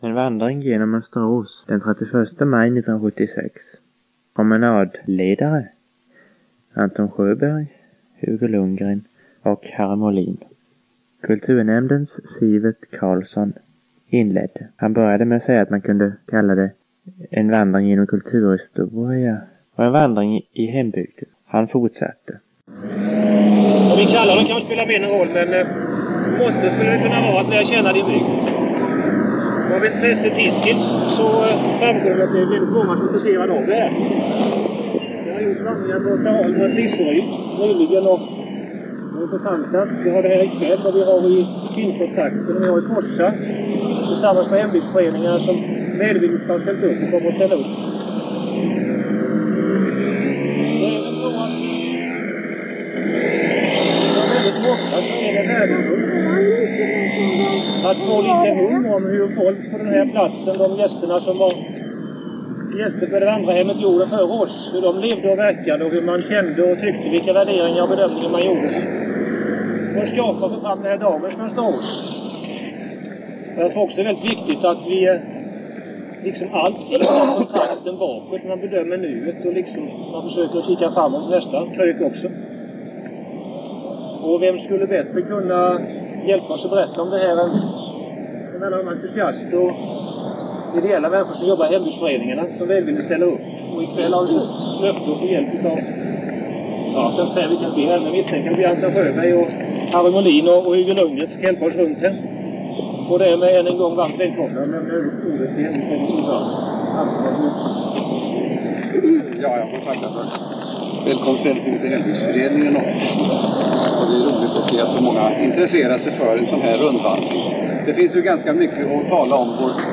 Allt detta är inspelat med kassettbandspelare, ljudkvaliteten är därför skiftande. 1976 hade dessutom biltrafiken full tillgång till alla gator, varför det är ett konstant buller (trots att affärerna väl hade stängt klockan 18 på kvällen när vandringen började).